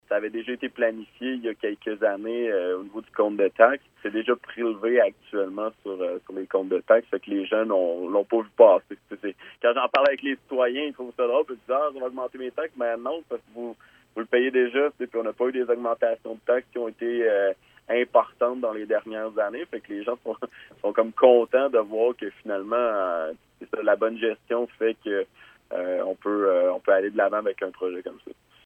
Voici ce que le maire de Marieville avait à dire sur la part restante.